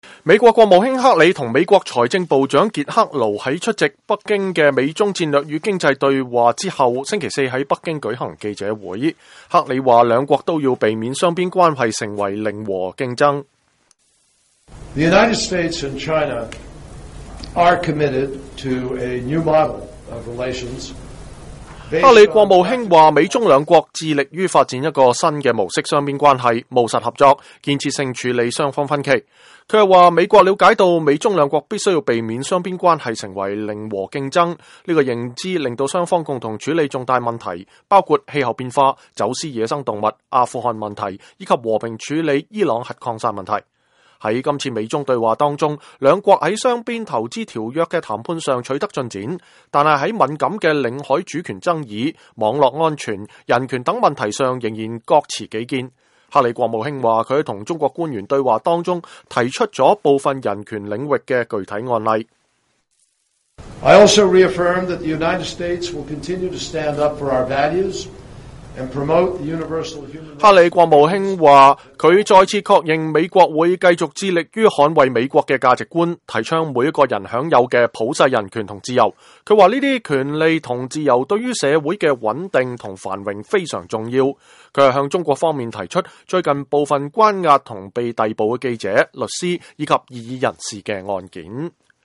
克里在北京舉行記者會